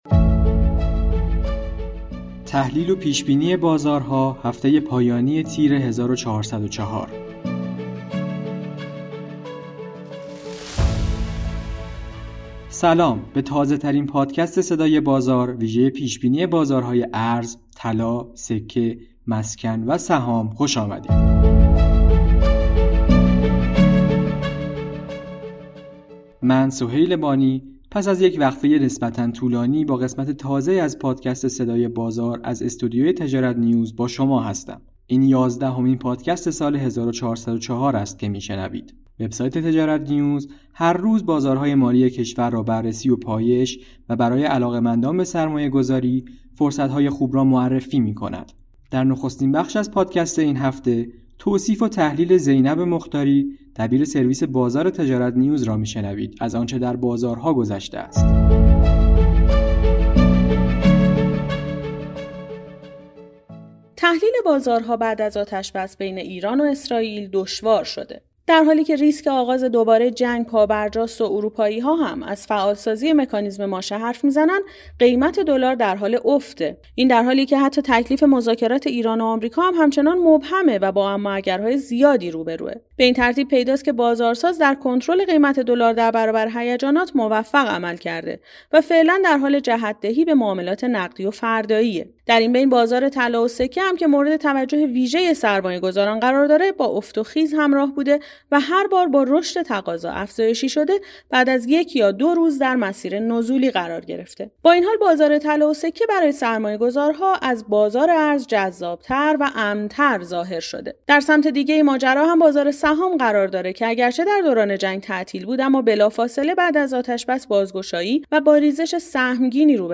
به گزارش تجارت نیوز، به تازه‌ترین پادکست صدای بازار ویژه پیش بینی بازارهای ارز، طلا، سکه، مسکن و سهام خوش آمدید.